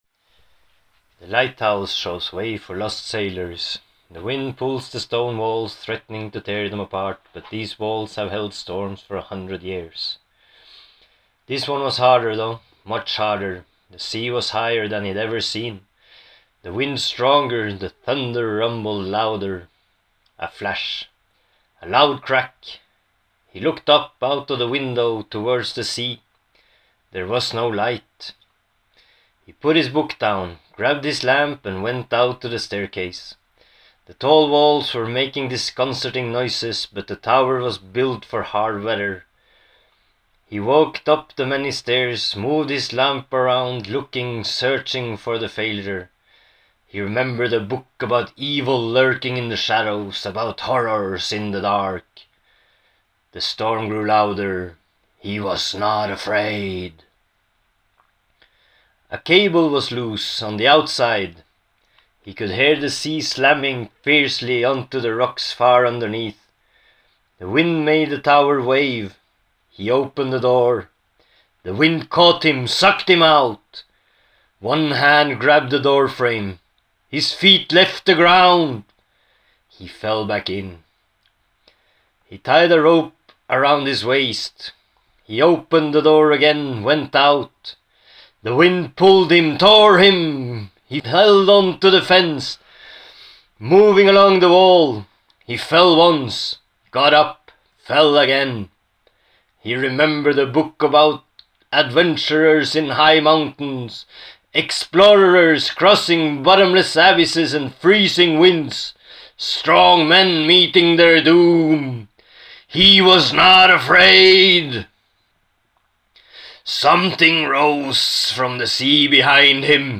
Rewritten, read out loud and republished.